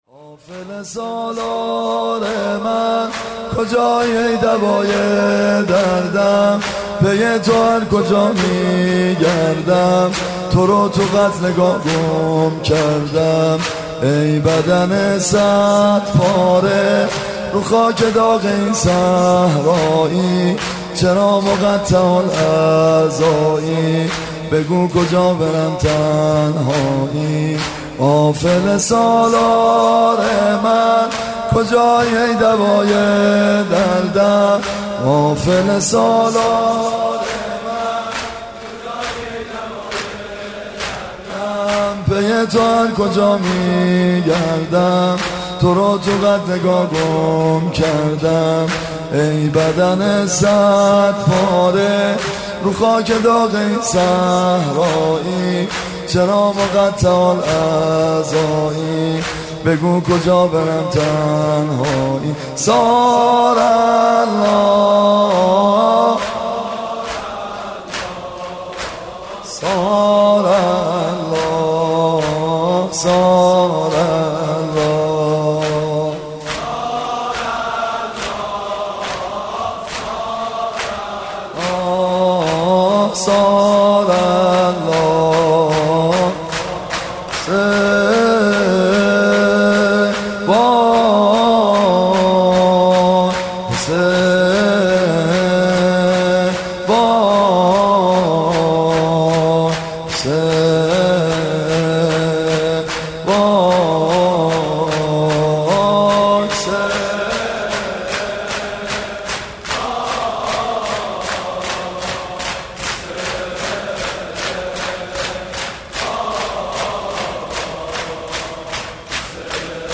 عقیق:مراسم عصر عاشورا محرم95/هیئت میثاق با شهدا
شور/قافله سالار من